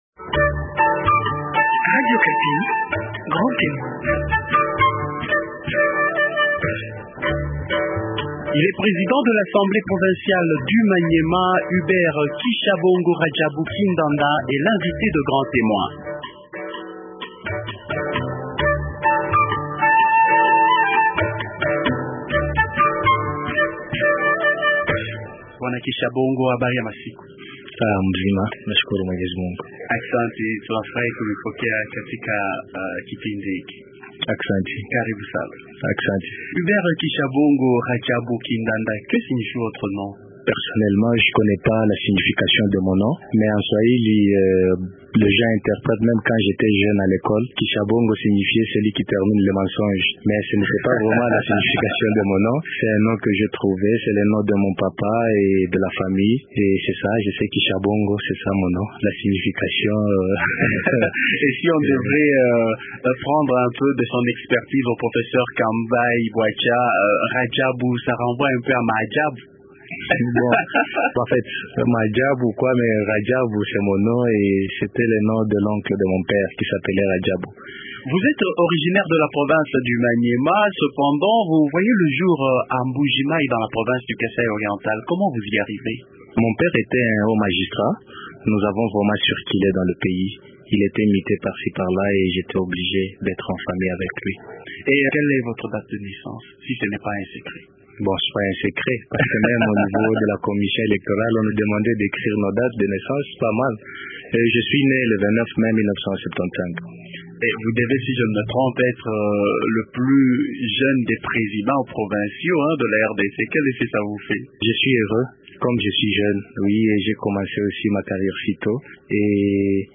Hubert Kishabongo Radjabu Kindanda est président de l’assemblée provinciale du Maniema.